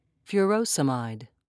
(fur-oh'se-mide)